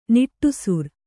♪ niṭṭusur